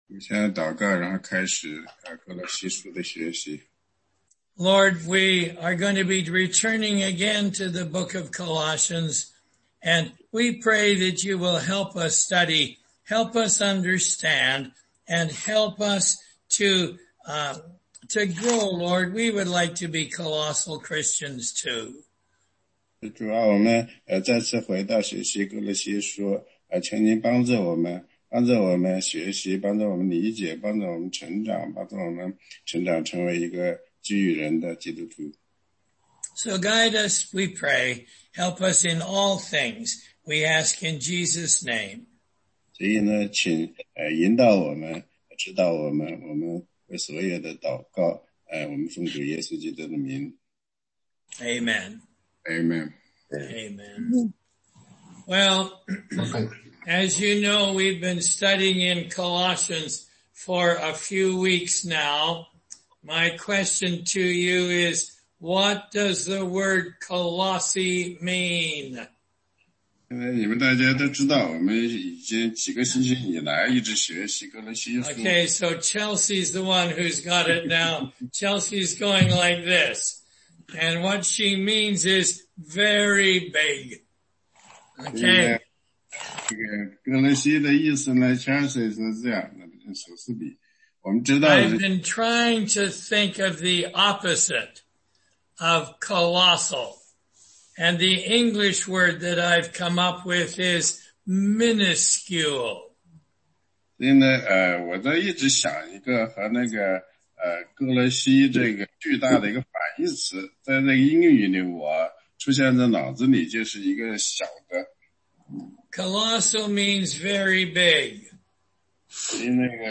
16街讲道录音 - 歌罗西书3章1-17节：脱去旧人，穿上新人
答疑课程